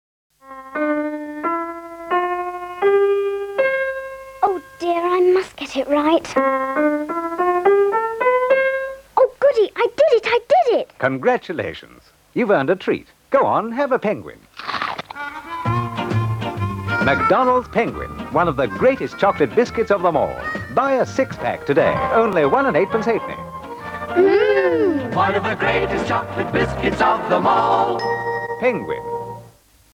Penguin biscuit advert.mp3